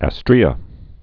(ă-strēə)